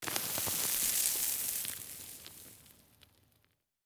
expl_debris_sand_03.ogg